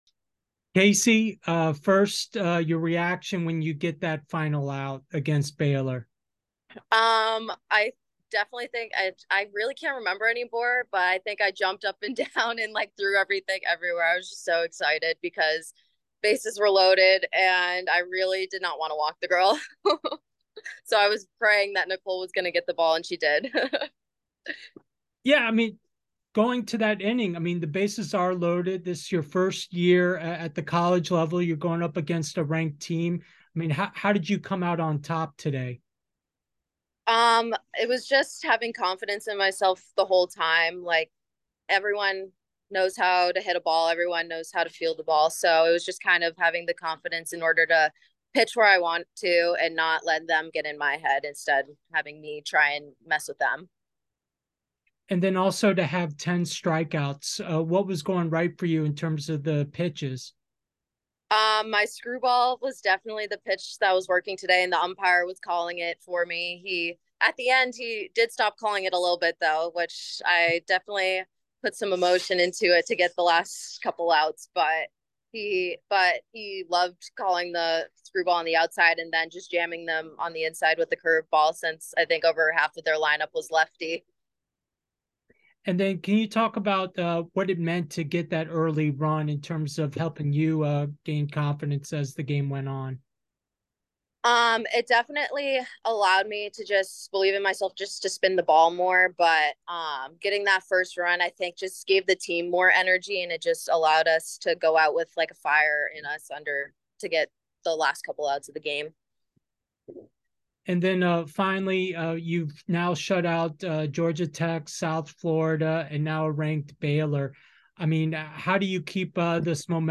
#12 Baylor Postgame Interview